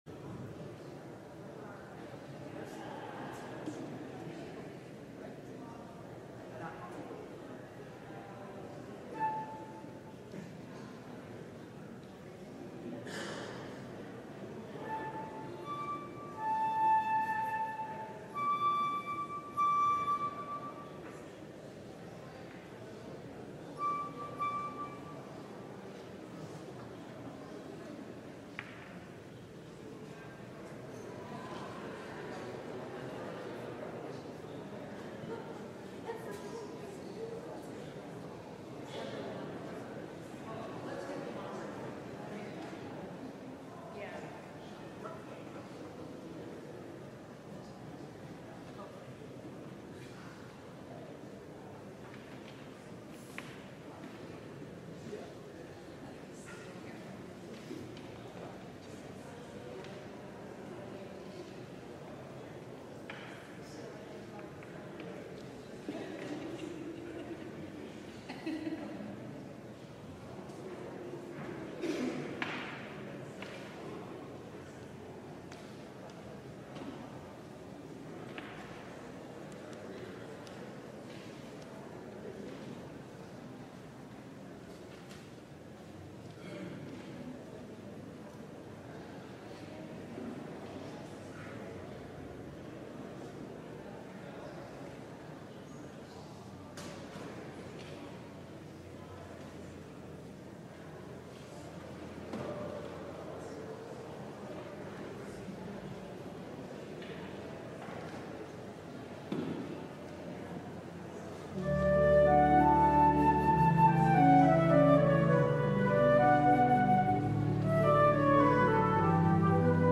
LIVE Midday Worship Service - The Imperfect Anointed: The Death of Saul